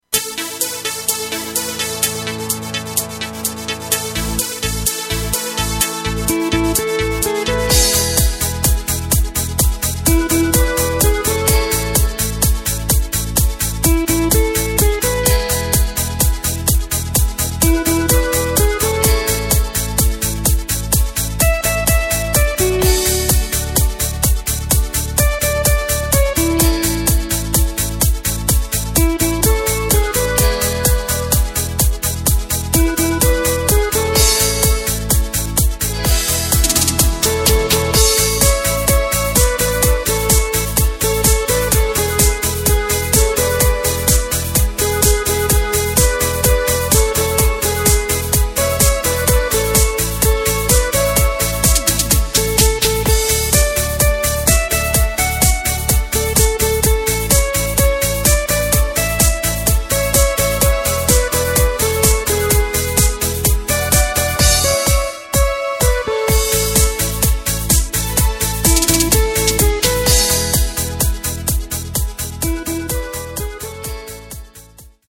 Takt:          4/4
Tempo:         127.00
Tonart:            A
Discofox aus dem Jahr 2015!